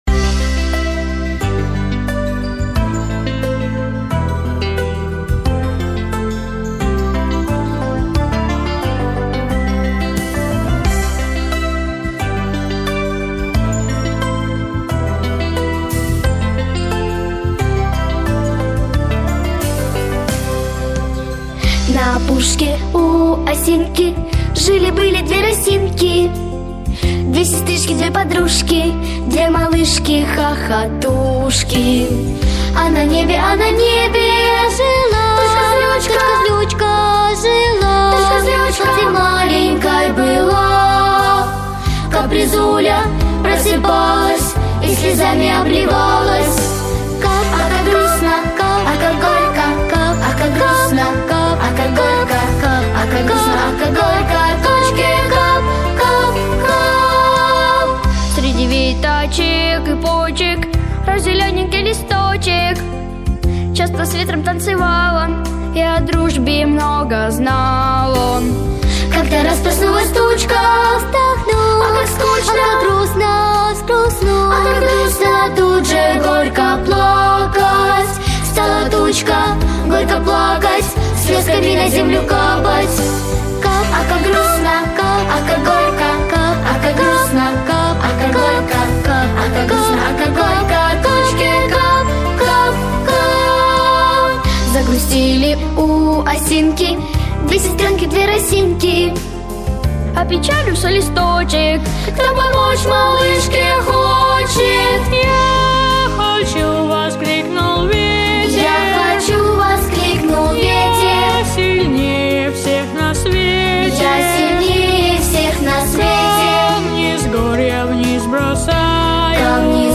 ГлавнаяПесниСовременные детские песни